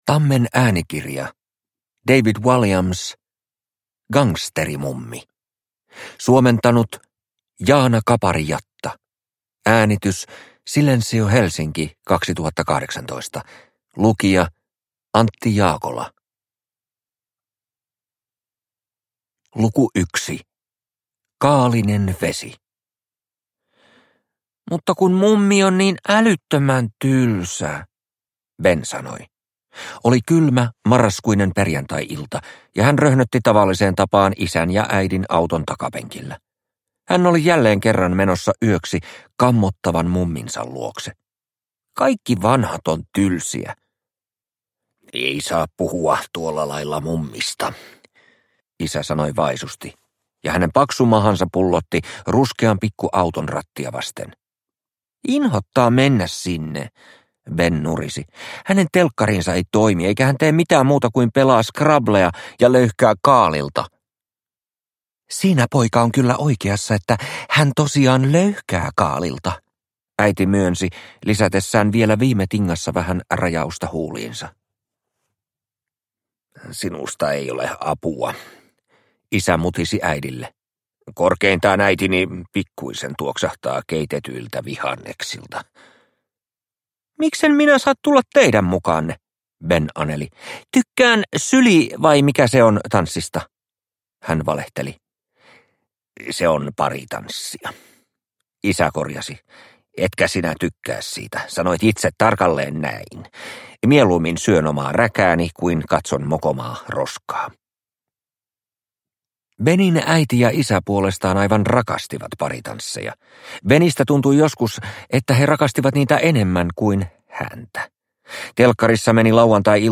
Gangsterimummi – Ljudbok – Laddas ner